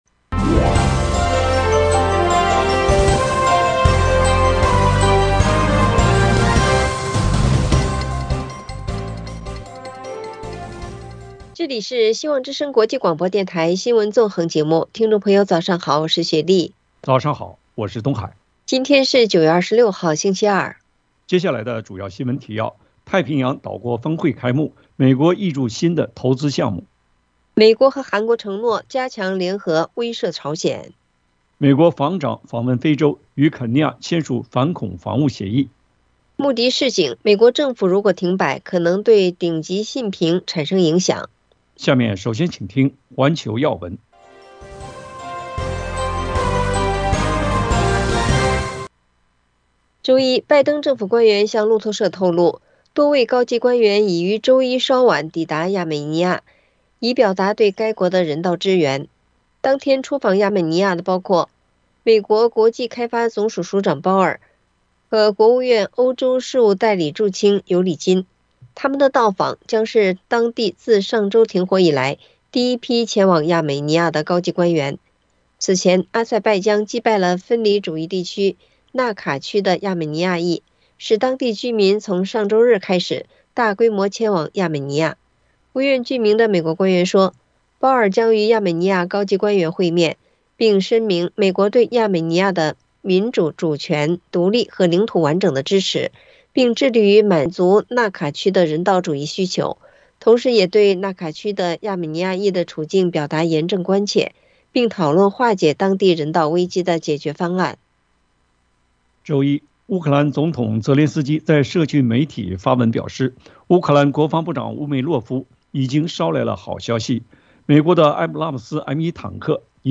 美国和韩国承诺加强联合威慑朝鲜【晨间新闻】